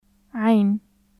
日本語の「ア」を喉の奥から出すようなつもりで発音してみてください。 /ʕ/ 有声・咽頭・摩擦音/ʕ/ ع /ʕayn/ ʕ （アルファベット） عائلة /ʕaːʔila/ 家族 عرب /ʕarab/ アラブ人 عيد /ʕiːd/ 祭り，祝日 سعيد /saʕiːd/ 幸せ السعودية /ʔs suʕuːdiyya/ サウジ عمان /ʕumaːn/ オマーン معنى /maʕnaː/ 意味 نعناع /naʕnaːʕ/ ミント